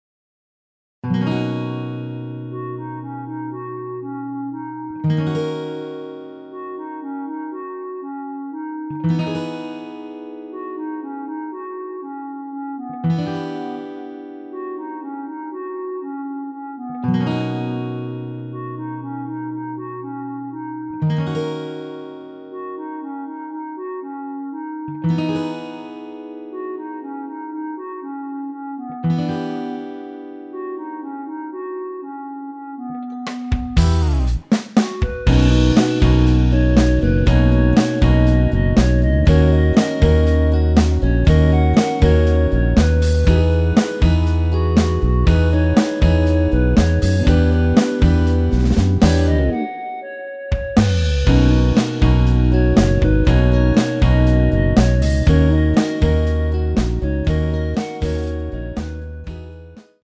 원키 멜로디 포함된 MR입니다.
노래방에서 노래를 부르실때 노래 부분에 가이드 멜로디가 따라 나와서
앞부분30초, 뒷부분30초씩 편집해서 올려 드리고 있습니다.